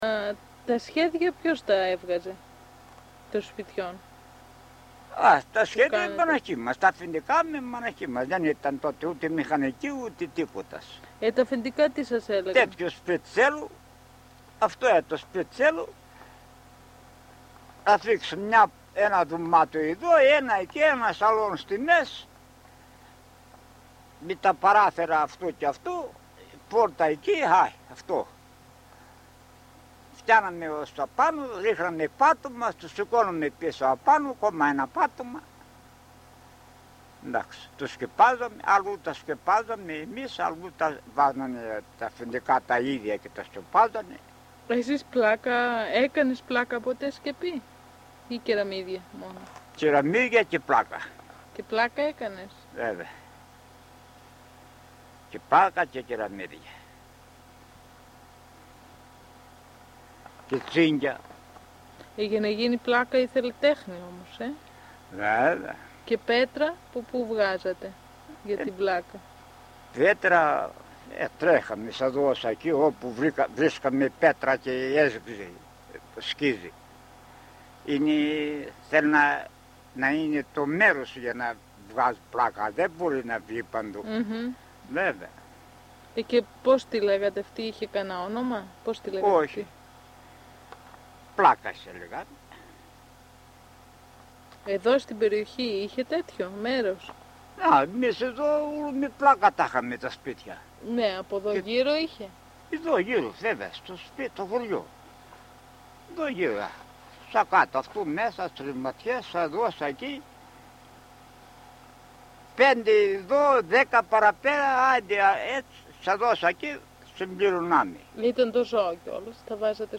Έρευνα στα Μαστοροχώρια της Δυτικής Μακεδονίας, από το επιστημονικό προσωπικό του ΛΕΜΜ-Θ.
Συνέντευξη με ηλικιωμένο άντρα, που άσκησε το επάγγελμα του μάστορα- χτίστη ("καρφά"): τα σχέδια των σπιτιών. (EL)
Δυτική Μακεδονία / Μακεδονία / Ελλάδα (EL)